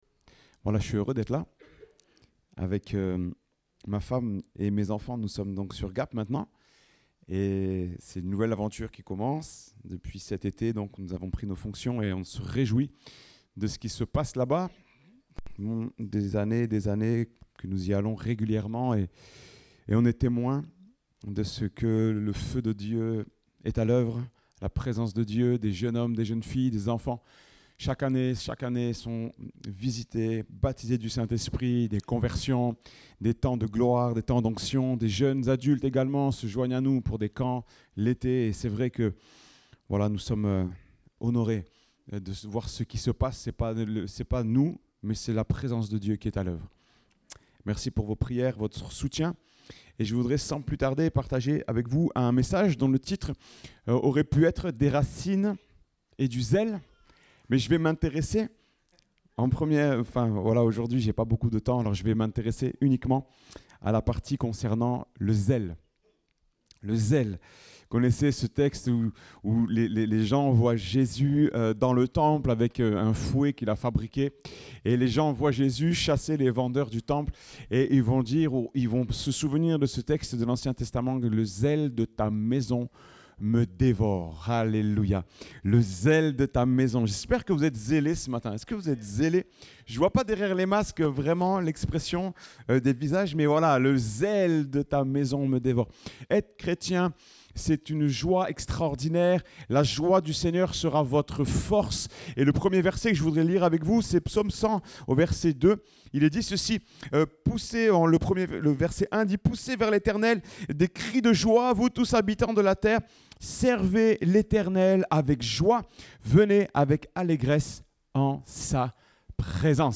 Date : 10 octobre 2021 (Culte Dominical)